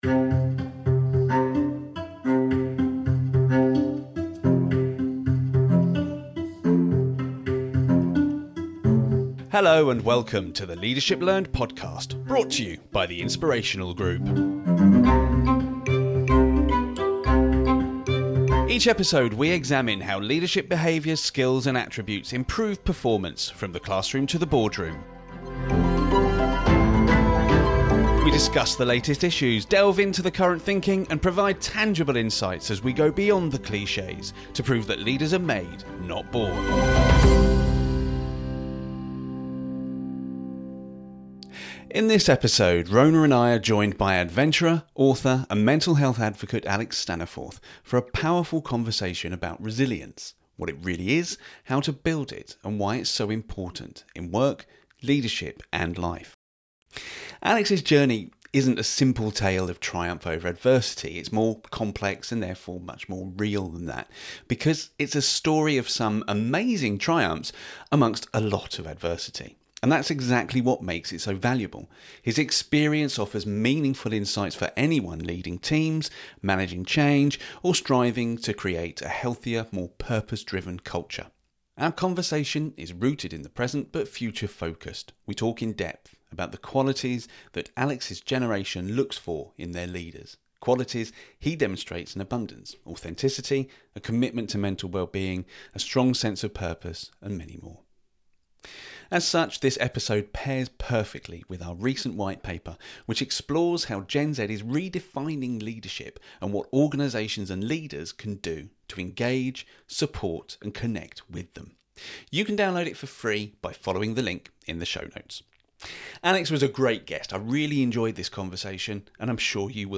This isn’t a polished tale of glory—it’s a deeply human conversation about setbacks, self-awareness, and the strength that comes from struggle.